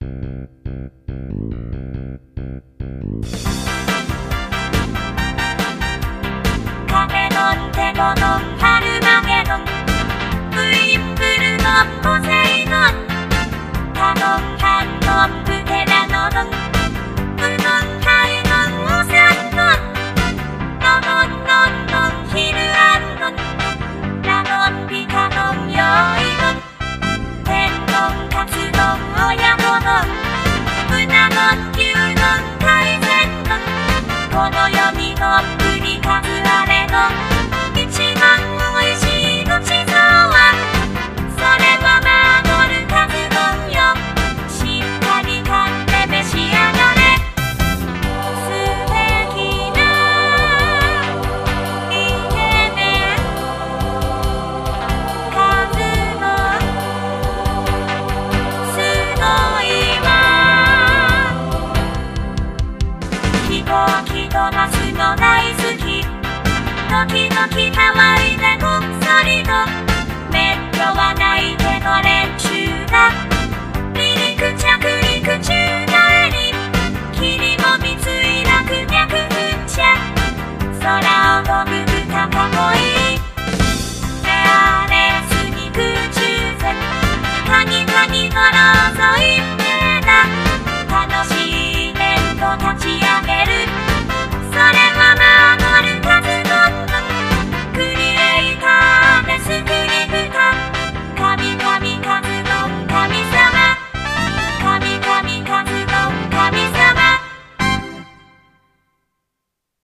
サポセンジャー お花見コンサート。
ギター I
ベース
キーボード
ドラムス